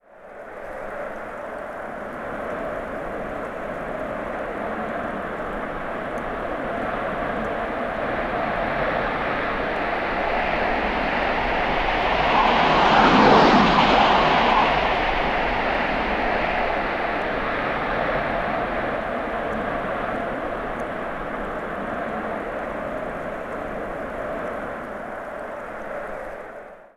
Avión F18 pasando 1
sobrevolar
Sonidos: Transportes